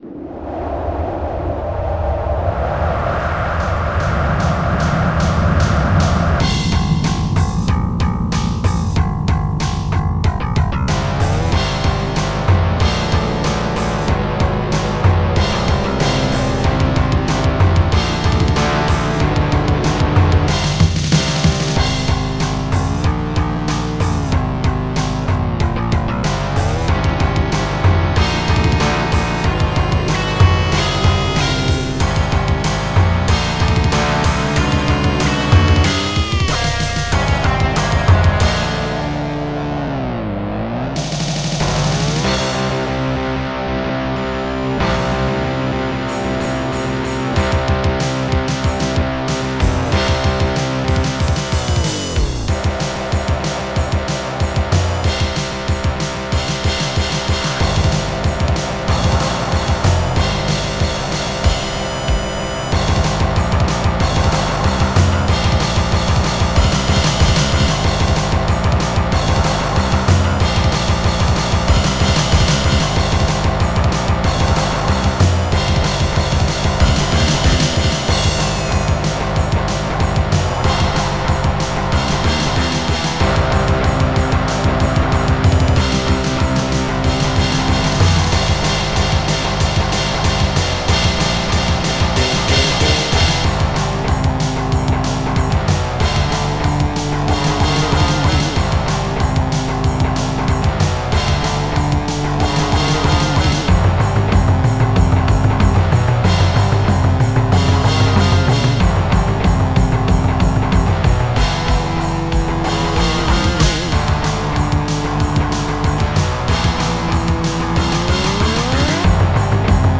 bass NMR
guitarsolo
Bass Drum01
cymbal NOIS
snare NOIS